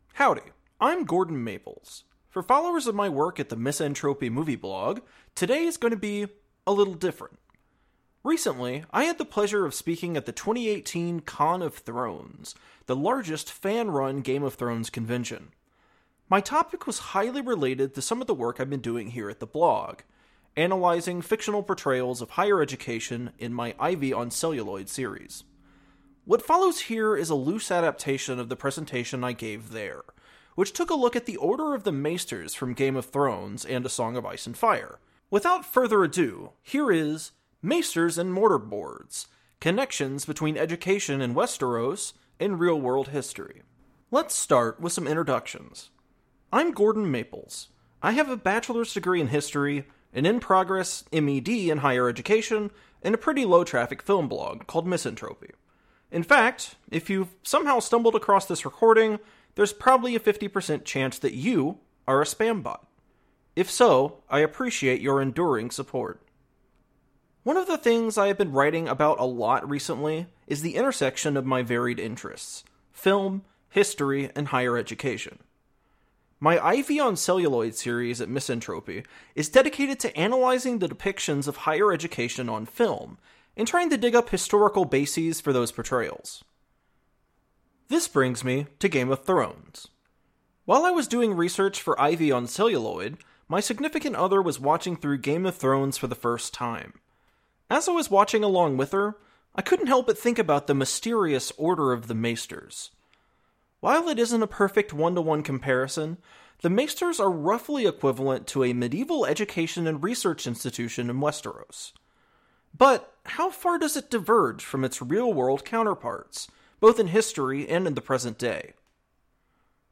Recently, I had the pleasure of speaking at the 2018 Con of Thrones, the largest fan-run Game of Thrones convention. My topic was highly related to the work I've been doing here: analyzing fictional portrayals of higher education.